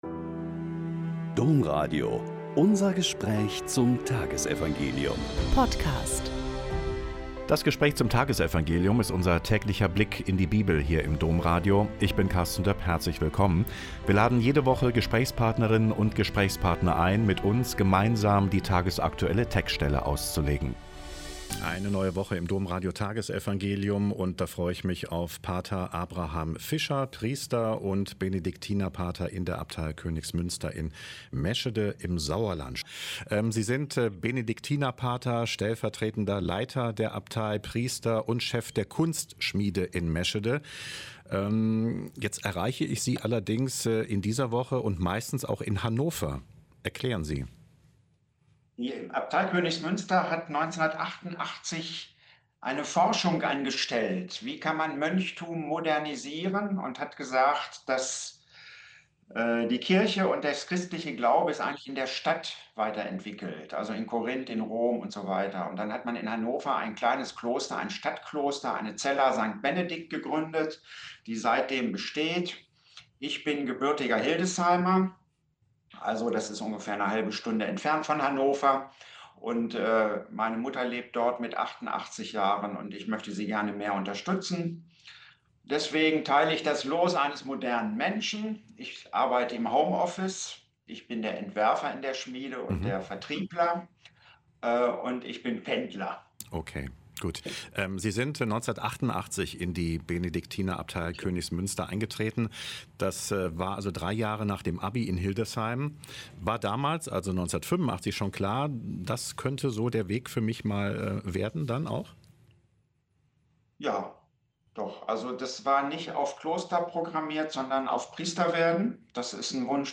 Lk 21,1-4 - Gespräch